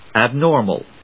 /æbnˈɔɚm(ə)l(米国英語), æˈbnɔ:rmʌl(英国英語)/
フリガナアブノーマル